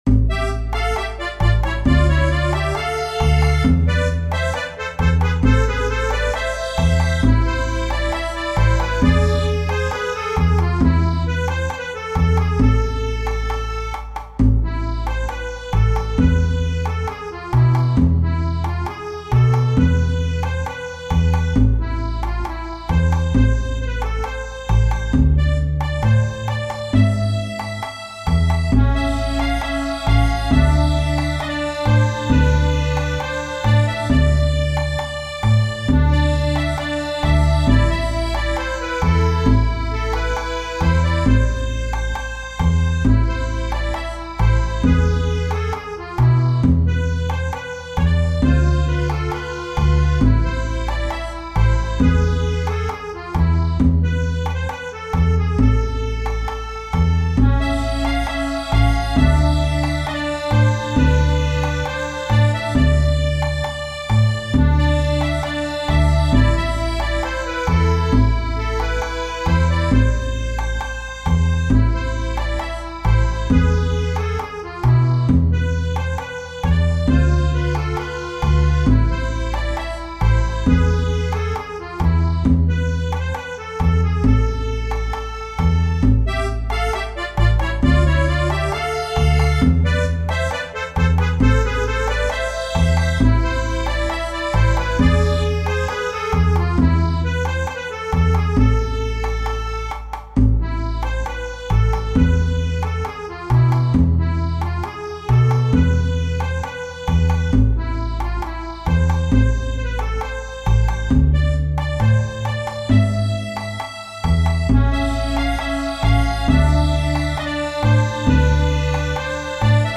Tradizionale Genere: Folk "Eench Eemanaee" (in italiano: come potevo saperlo) è un brano popolare armeno derivato dal celebre "Miserlou" greco.